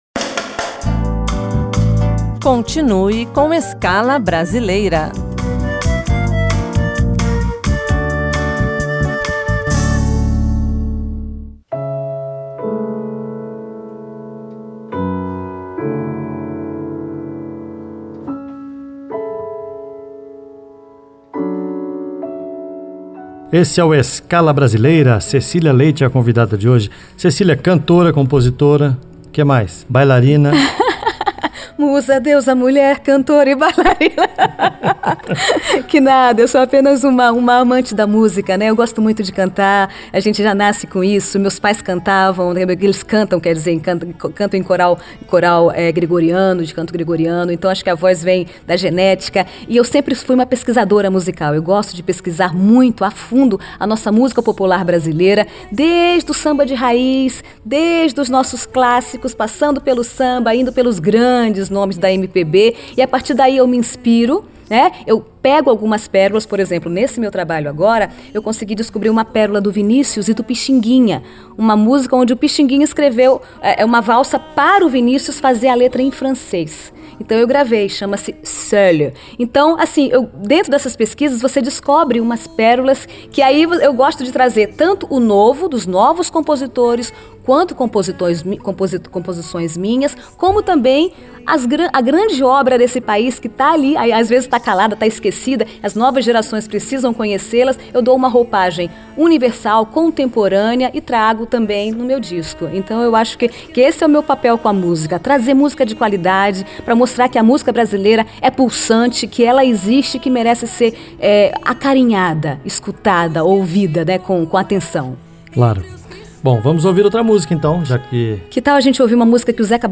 Cantora.